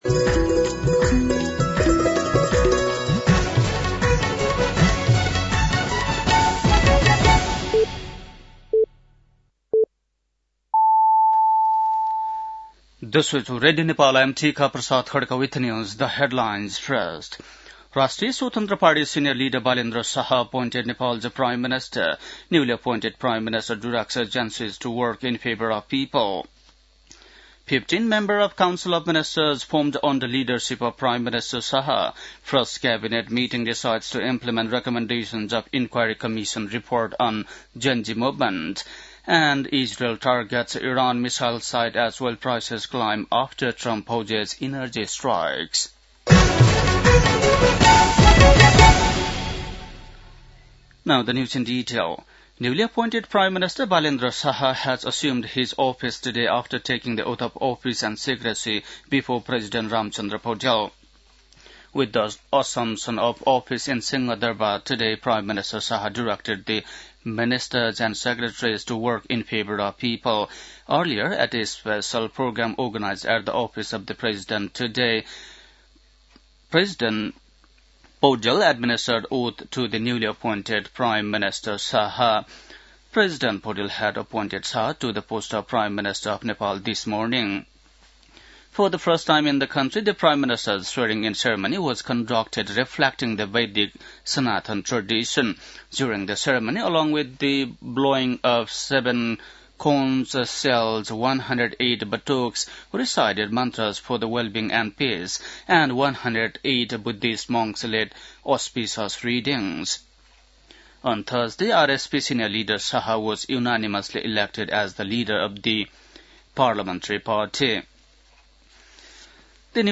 बेलुकी ८ बजेको अङ्ग्रेजी समाचार : १३ चैत , २०८२